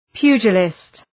Προφορά
{‘pju:dʒəlıst} (Ουσιαστικό) ● πυγμάχος